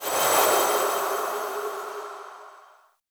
UI Whoosh Notification 6.wav